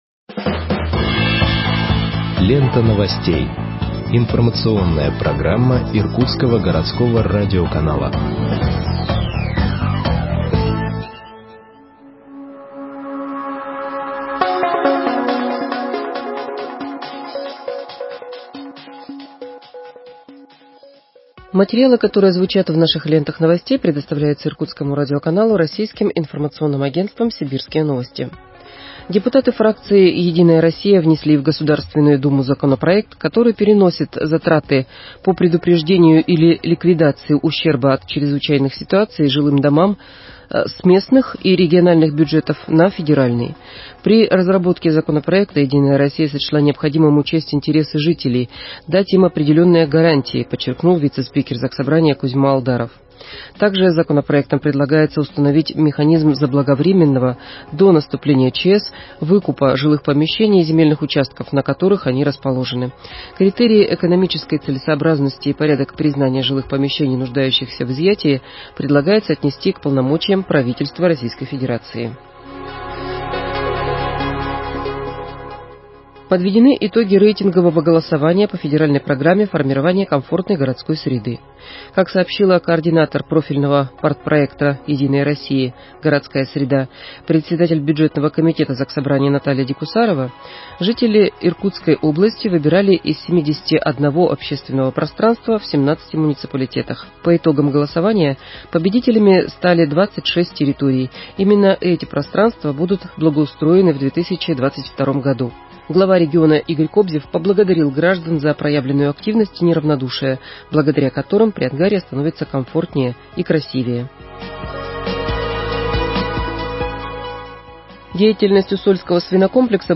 Выпуск новостей в подкастах газеты Иркутск от 10.06.2021 № 1